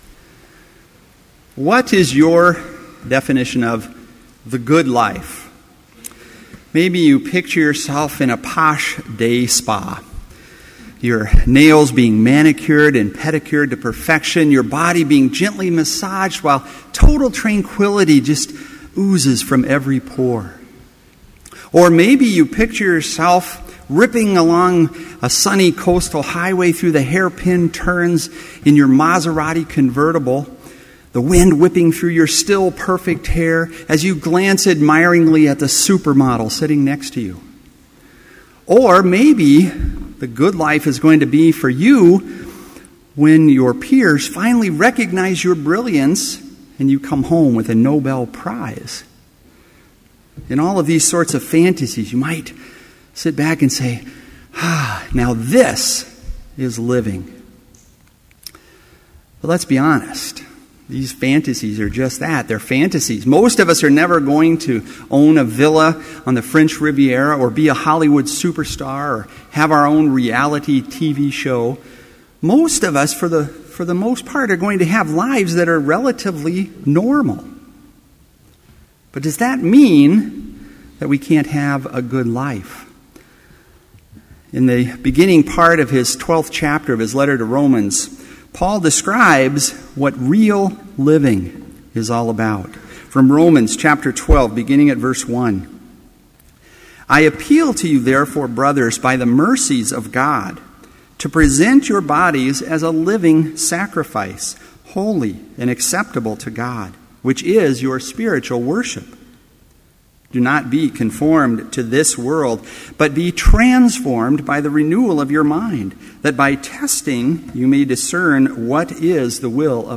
Complete Service
• Prelude
• Homily
• Postlude
This Chapel Service was held in Trinity Chapel at Bethany Lutheran College on Tuesday, September 3, 2013, at 10 a.m. Page and hymn numbers are from the Evangelical Lutheran Hymnary.